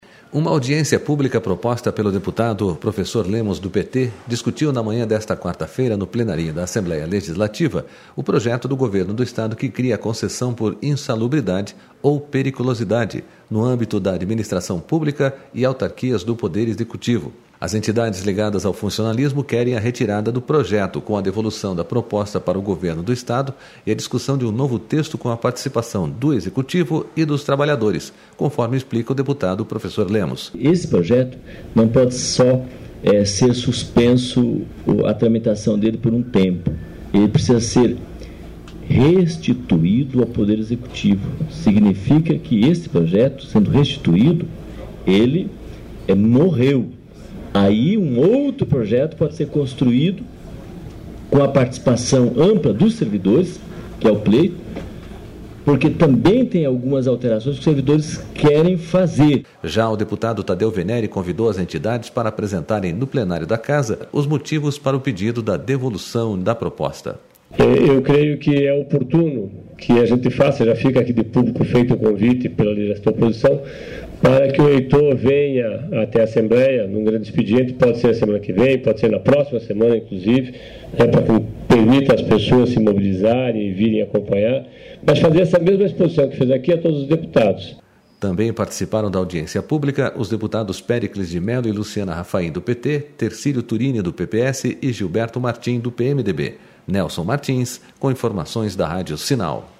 Uma audiência pública proposta pelo deputado Professor Lemos, do PT, discutiu na manhã desta quarta-feira, no Plenarinho da Assembleia Legislativa, o projeto do Governo do Estado, que cria a concessão por insalubridade ou periculosidade no âmbito da Administração Pública e autarquias do Poder Execut...